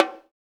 Index of /90_sSampleCDs/Roland - Rhythm Section/PRC_Latin 1/PRC_Conga+Bongo